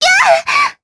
Erze-Vox_Damage_jp_02.wav